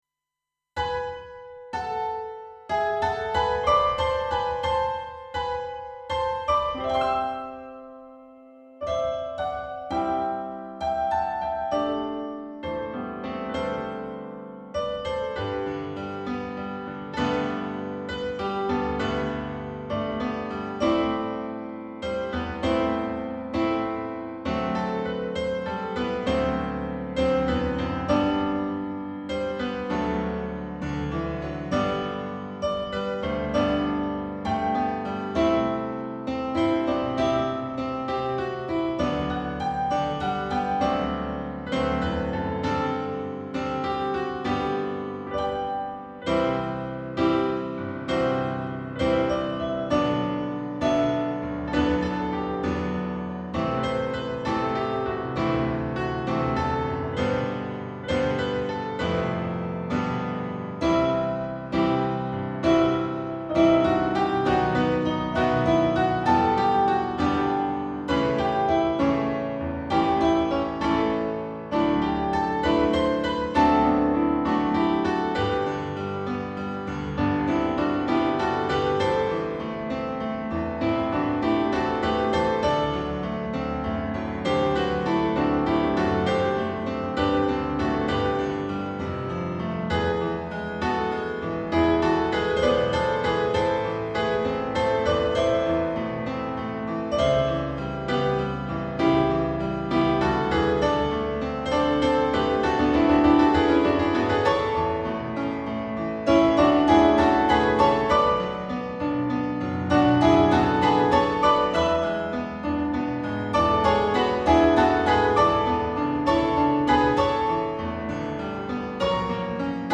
ピアノアレンジ版   GM版 ----
MP3はSD-90で鳴らしてます